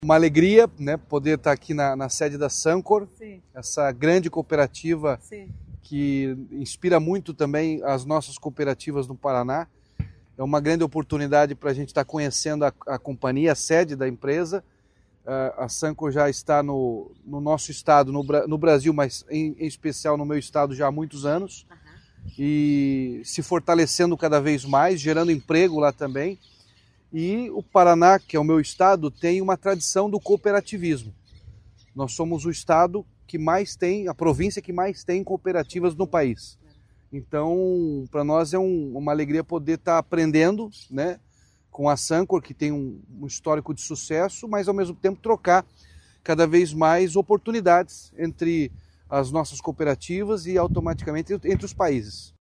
Sonora do governador Ratinho Junior sobre a visita à cooperativa e centro de inovação na Argentina